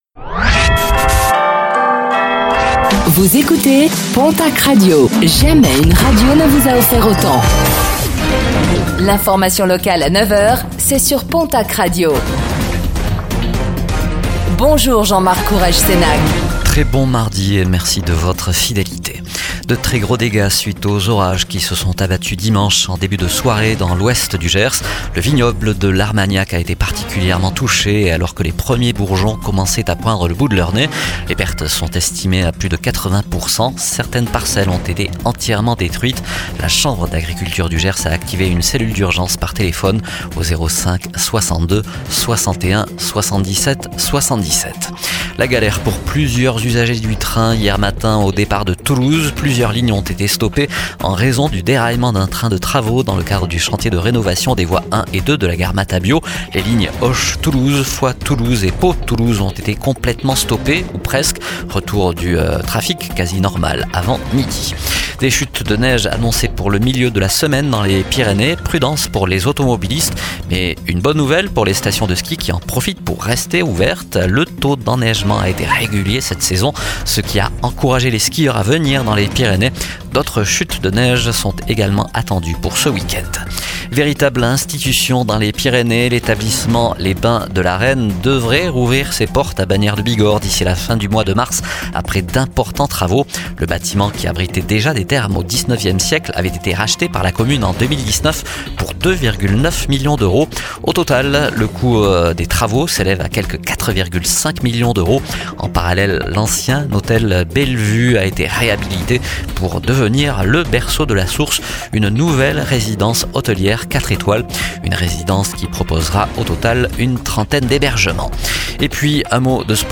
Infos | Mardi 11 mars 2025